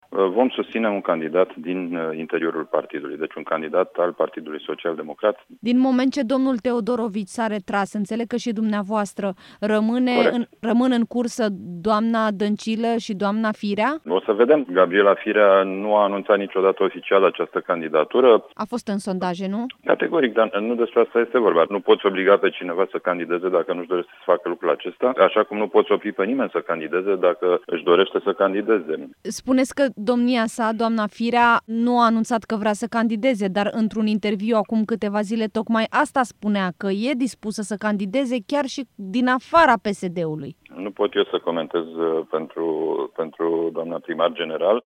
Într-un interviu acordat Europa FM, secretatul general al PSD, Mihai Fifor, vine cu explicaţii şi despre posibilitatea ca actualul primar general al Capitalei, Gabriela Firea, să fie candidatul PSD.